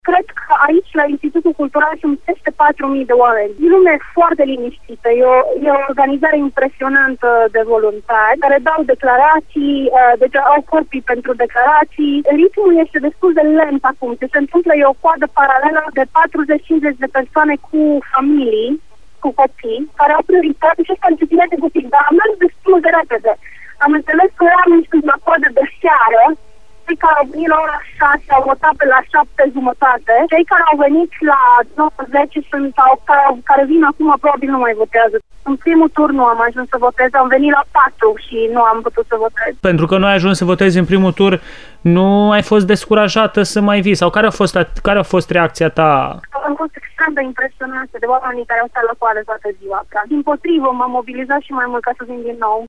poveste o timişoreancă